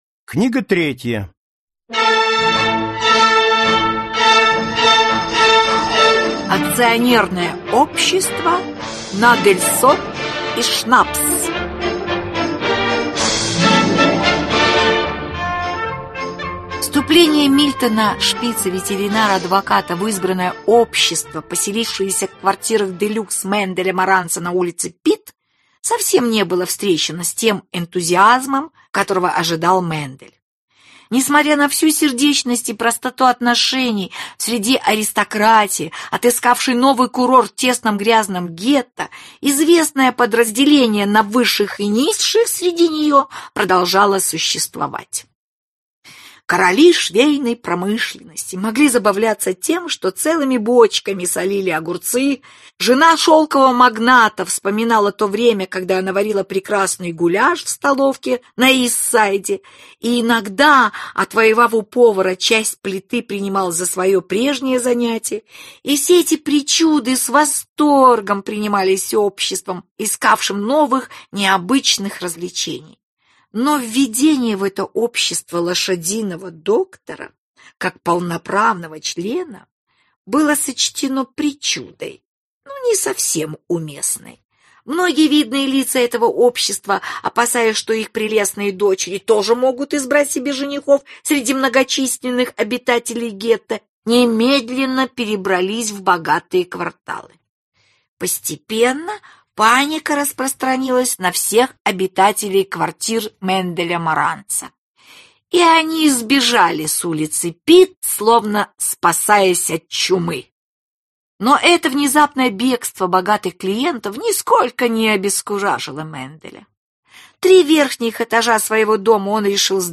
Аудиокнига Мендель Маранц. Книга третья. Акционерное общество «Надельсон и Шнапс» | Библиотека аудиокниг